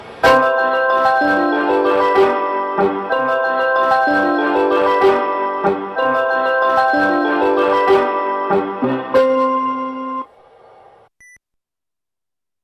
Using from effect sound collection.
Departure merody